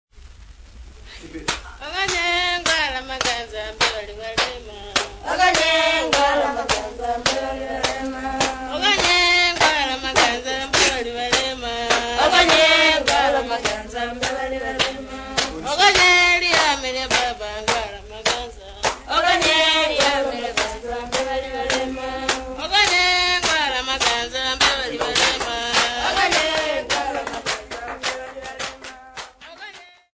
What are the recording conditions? Field recordings Africa Tanzania City not specified f-tz